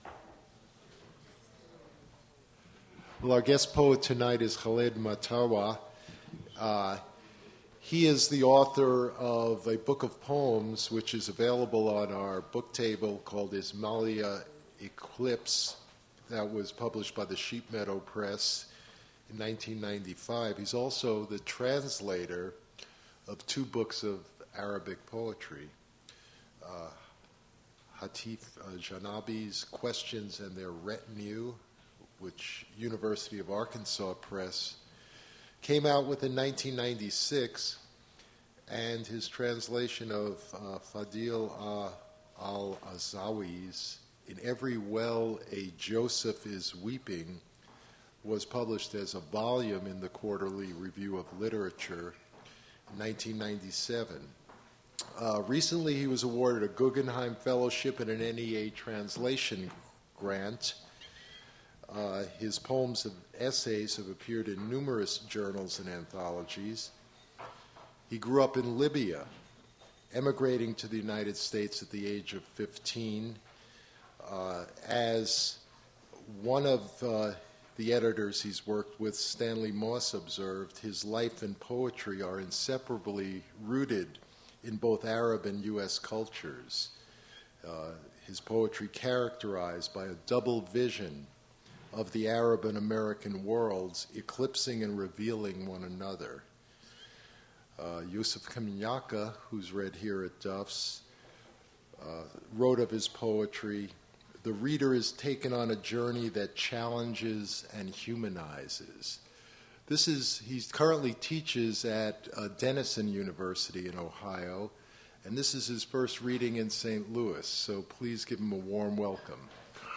Attributes Attribute Name Values Description Khaled Mattawa poetry reading at Duff's Restaurant.
mp3 edited access file was created from unedited access file which was sourced from preservation WAV file that was generated from original audio cassette.
audio gets a lot quieter at 2:45 when Mattawa starts speaking; 13:58-16:30 seems to be prose